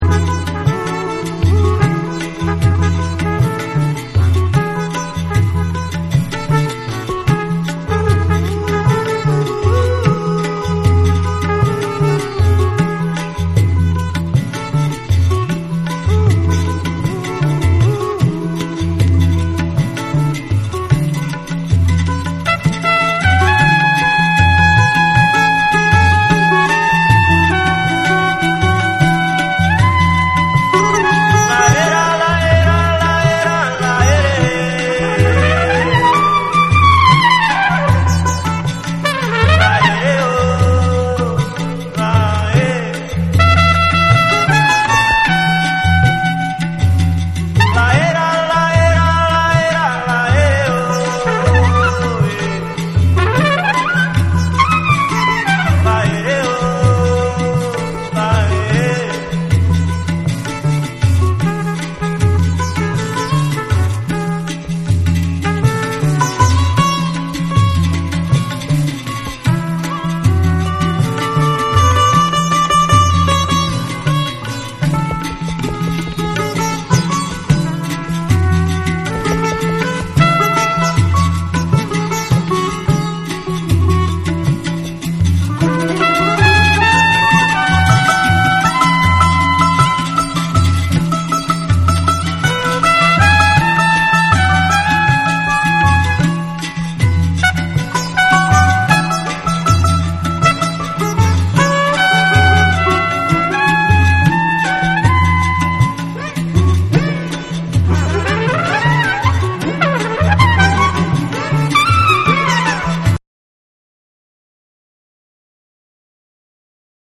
WORLD / NEW AGE & OTHERS